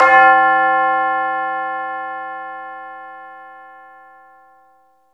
Tubular Bell 1.wav